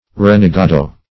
Renegado \Ren`e*ga"do\